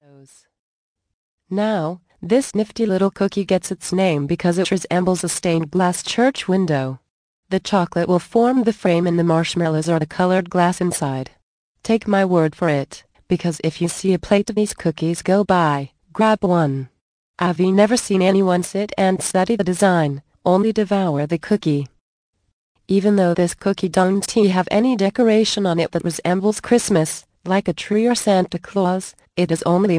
The Magic of Christmas audio book. Vol. 2 of 10 - 60min